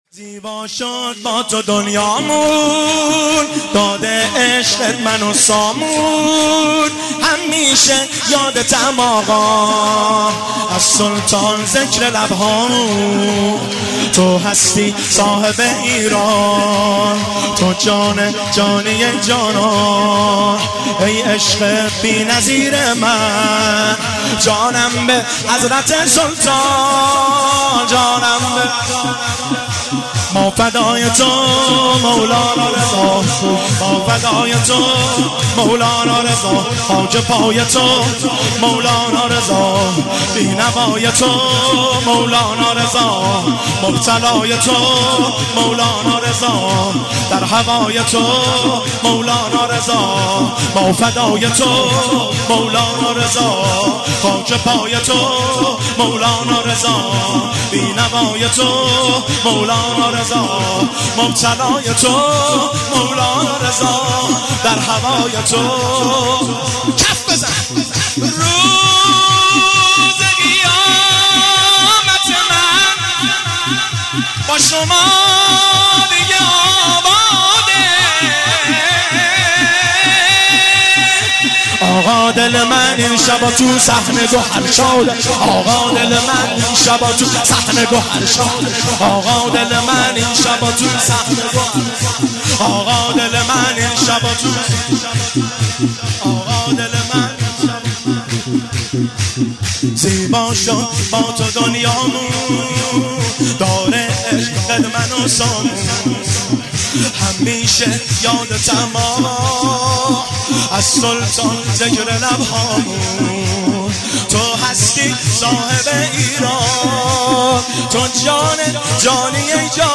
مراسم شب ولادت امام رضا(ع) – سال ۹۷
زیبا شد با تو دنیامون (سرود جدید)